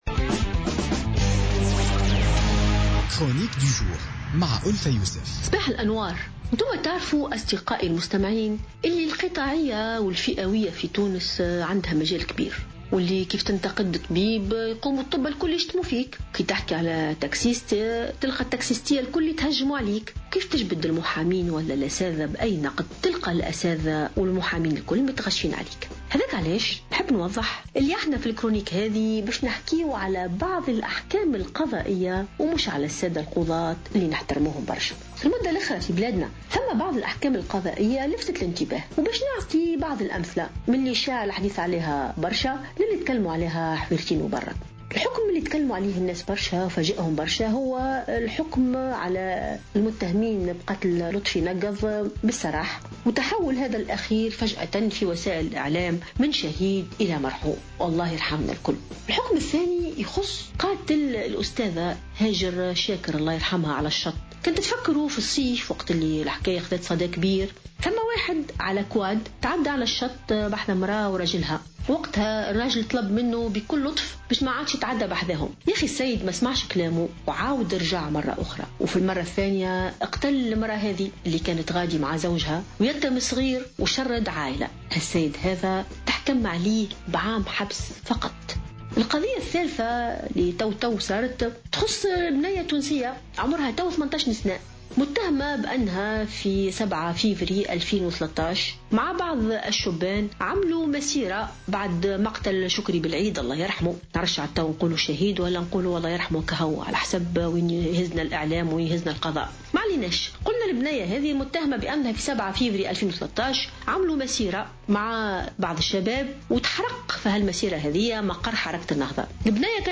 انتقدت الجامعية ألفة يوسف في افتتاحية اليوم الأربعاء 14 ديسمبر 2016 الأحكام القضائية الصادرة في بعض الملفات والقضايا.